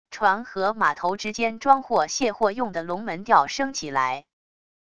船和码头之间装货卸货用的龙门吊升起来wav音频